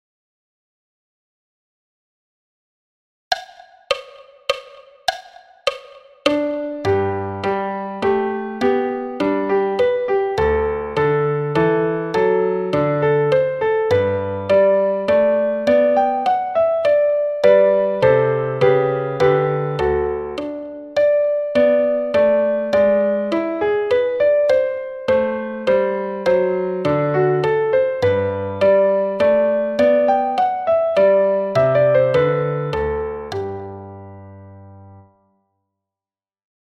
Menuet Haydn – piano et block à 102 bpm